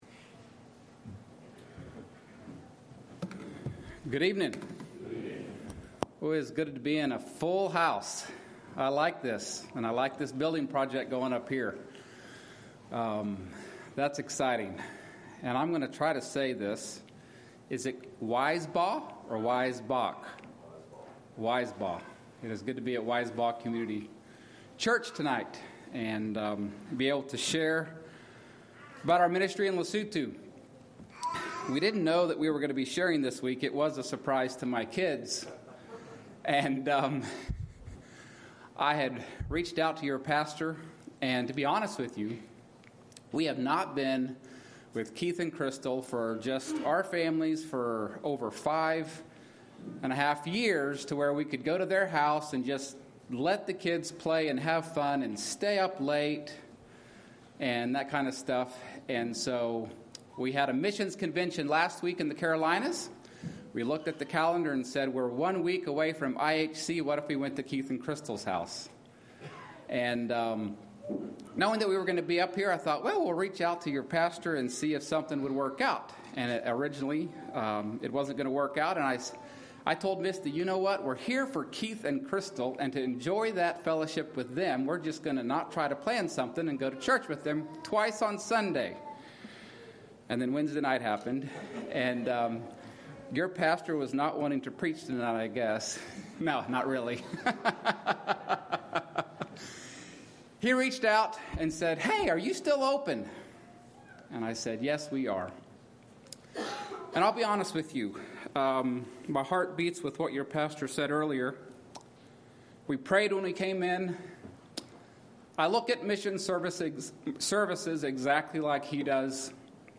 Missionary Service